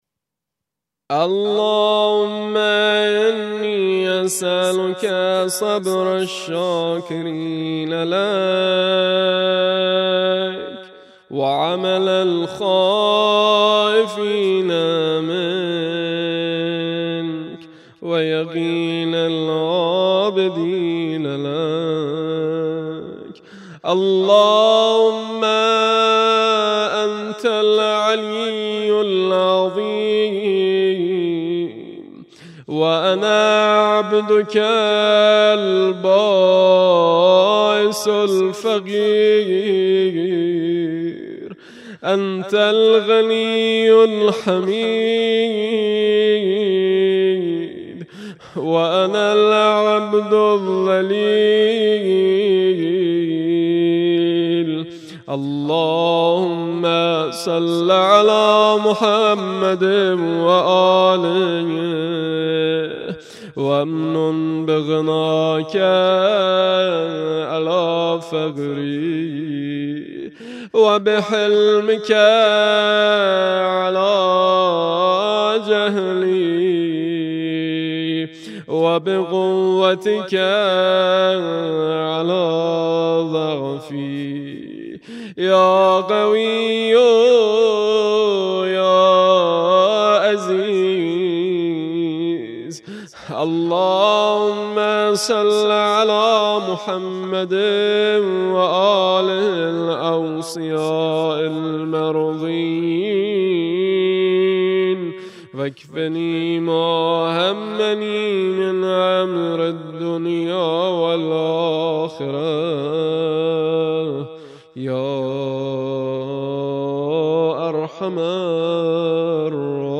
أدعية شهر رجب